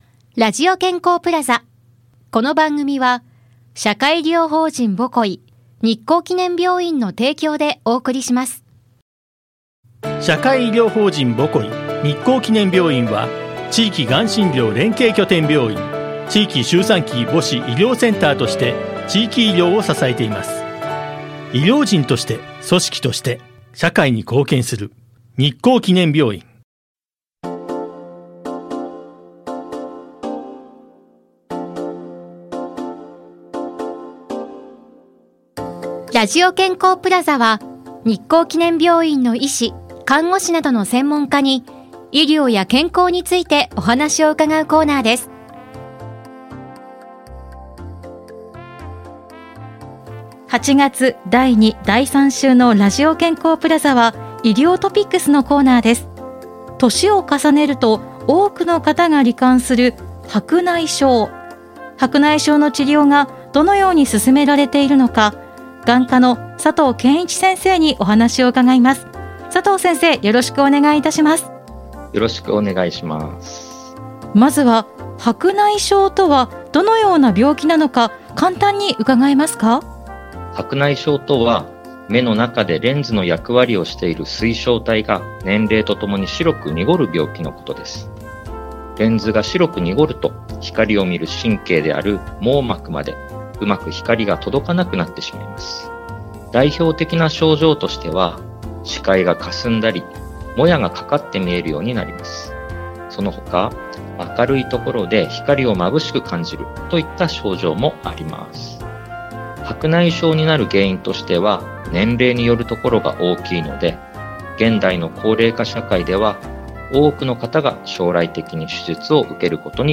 室蘭市のコミュニティFM『FMびゅー』から、様々な医療専門職が登場して、医療・健康・福祉の事や病院の最新情報など幅広い情報をお届けしています。